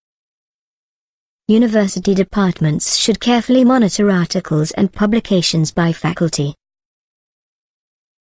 You will hear a sentence.